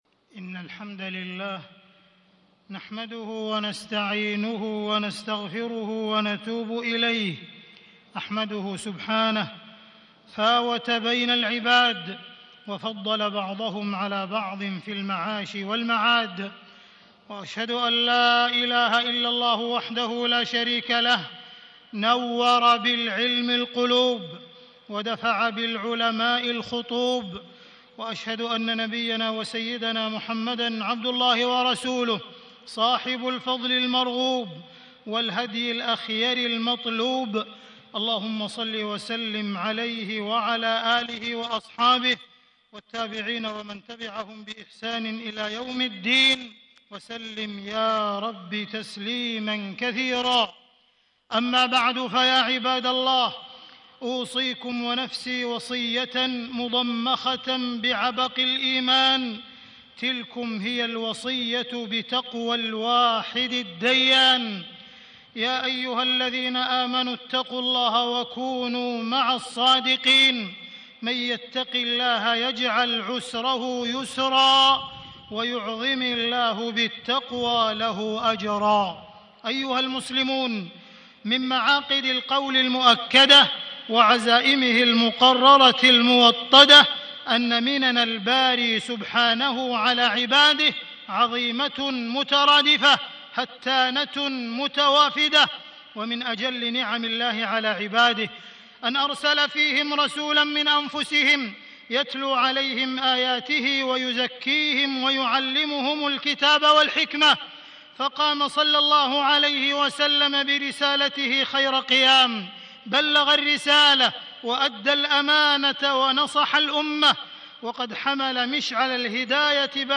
تاريخ النشر ٢٢ جمادى الأولى ١٤٣٦ هـ المكان: المسجد الحرام الشيخ: معالي الشيخ أ.د. عبدالرحمن بن عبدالعزيز السديس معالي الشيخ أ.د. عبدالرحمن بن عبدالعزيز السديس مكانة العلماء وواجباتهم